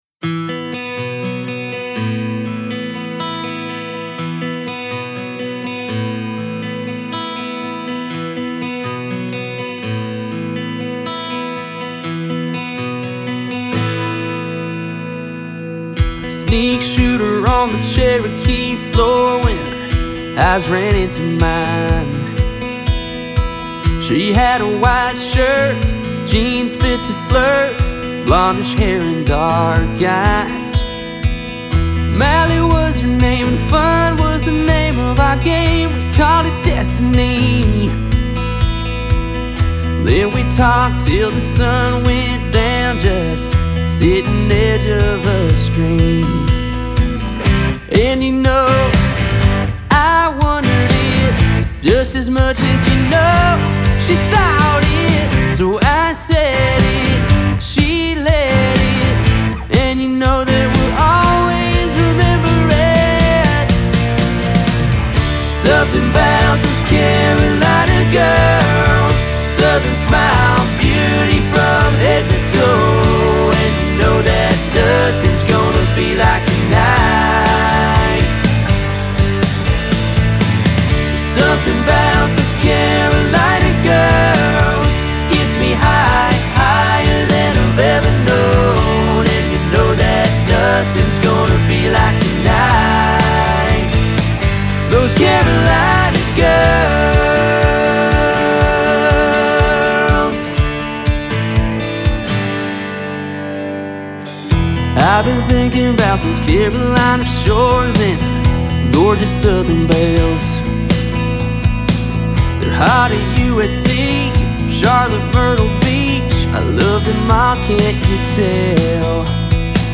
His latest production, Carolina Girls is an upbeat country track with an ode to his local ladies.
Since we were going for a modern country sound, selecting the right vocalist to deliver the vocals was important.
Using a combination of country guitars (both electric and acoustic), live drums, percussion and bass, we came up with a final product that is professional and ready to be pitched to artists.